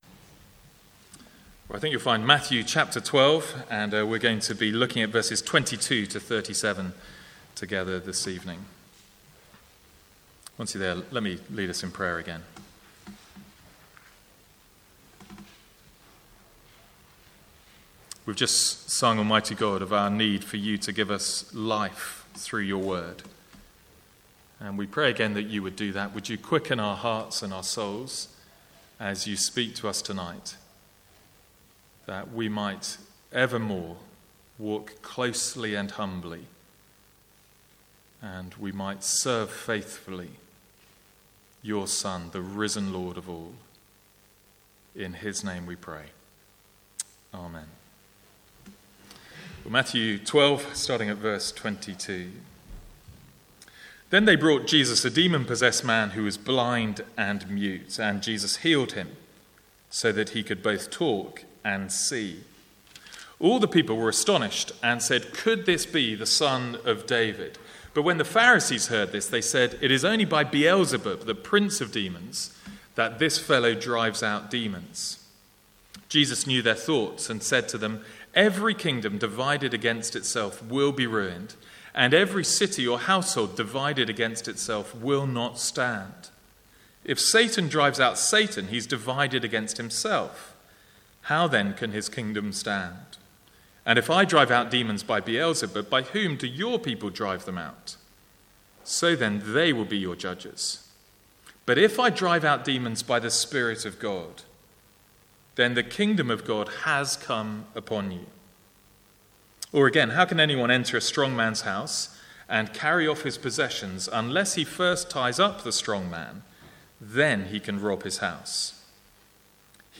From the Sunday evening series in Matthew.